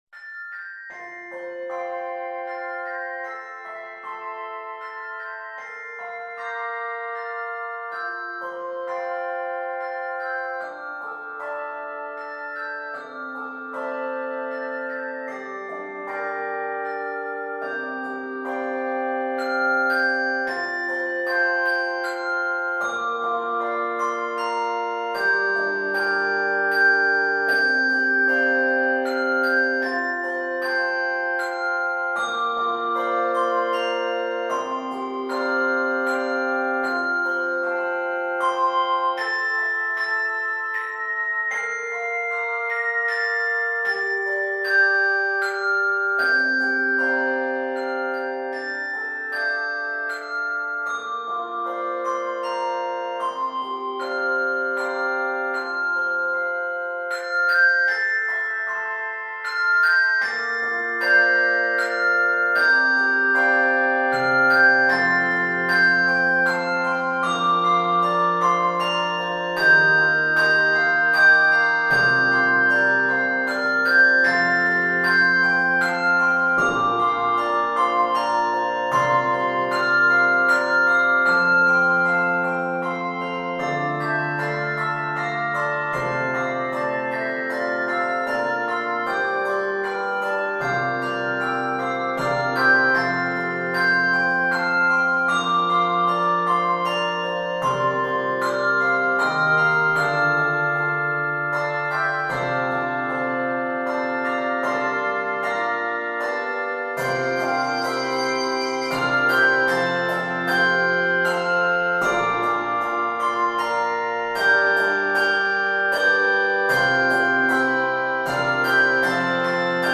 Key of F major.
Traditional English Carol Arranger